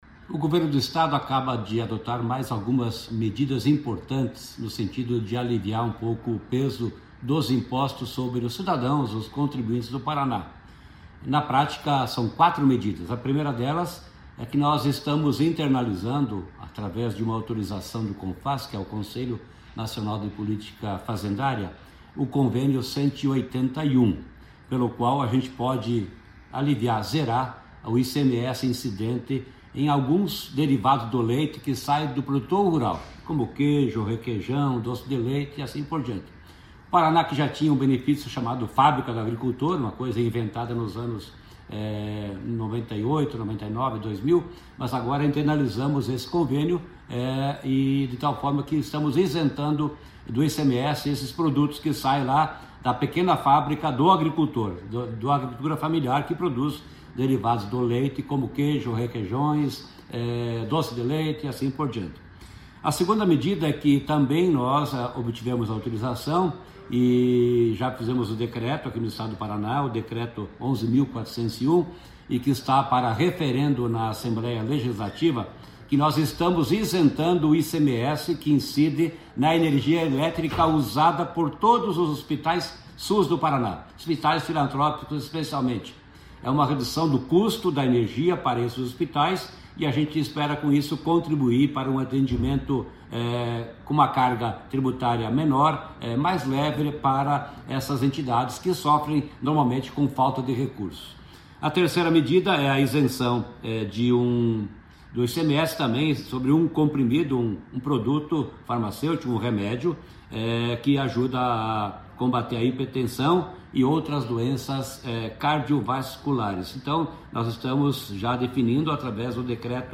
Sonora do secretário da Fazenda, Norberto Ortigara, sobre as novas medidas de isenção do ICMS no Paraná